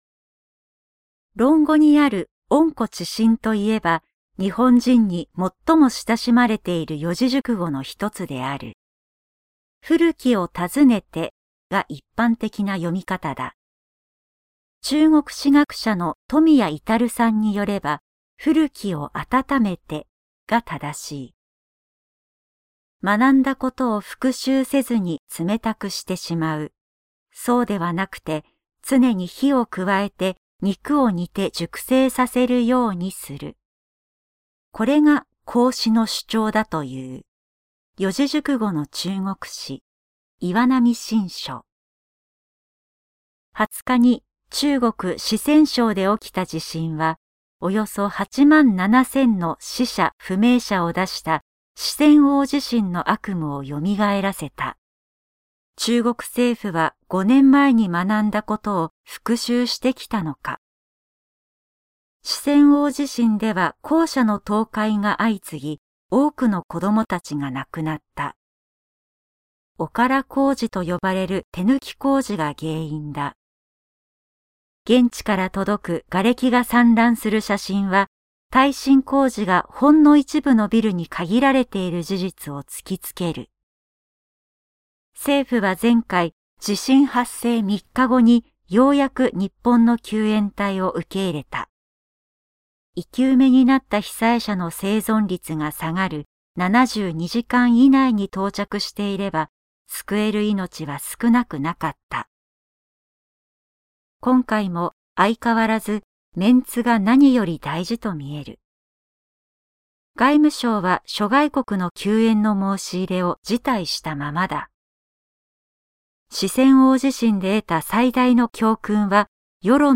産経新聞1面のコラム「産経抄」を、局アナnetメンバーが毎日音読してお届けします。